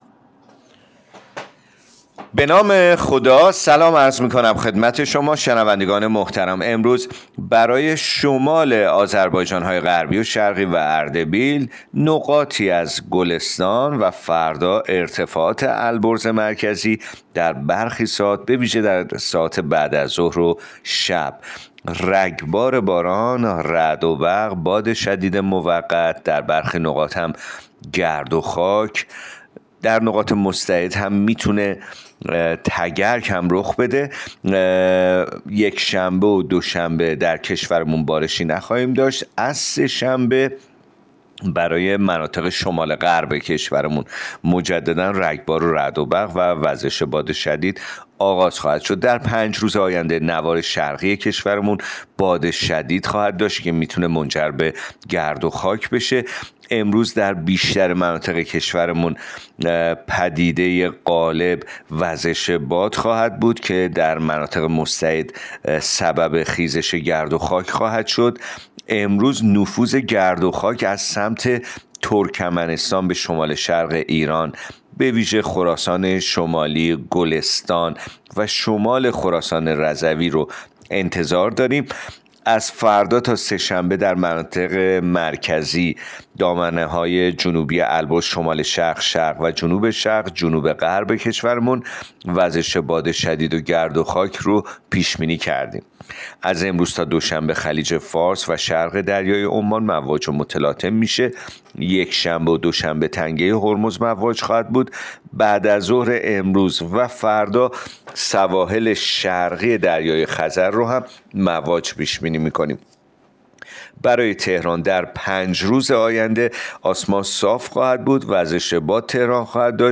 گزارش رادیو اینترنتی پایگاه‌ خبری از آخرین وضعیت آب‌وهوای ۹ خرداد؛